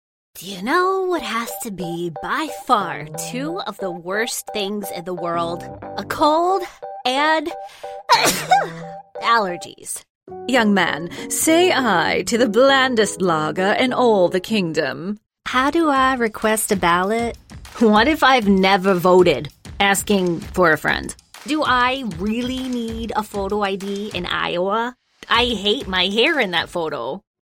Female Voice Over, Dan Wachs Talent Agency.
Bright, young, edgy, real person.